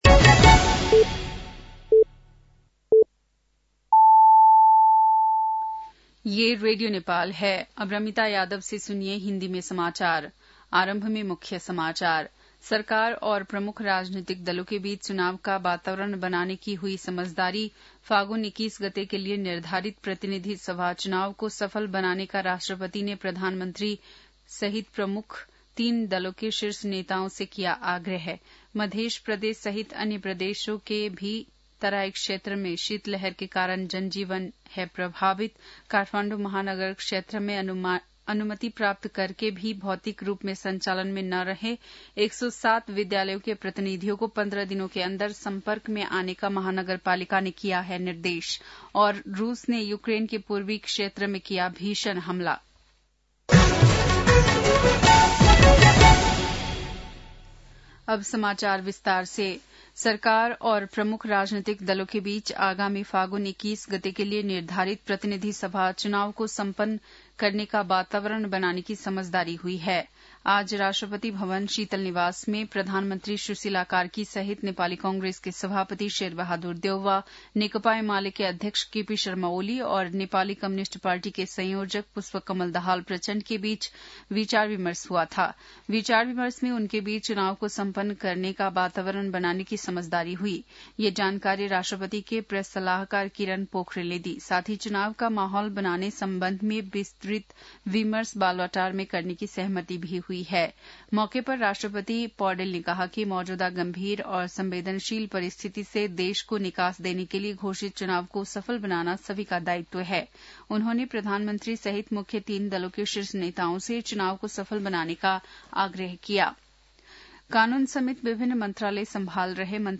बेलुकी १० बजेको हिन्दी समाचार : ८ पुष , २०८२
10-pm-hindi-news-9-08.mp3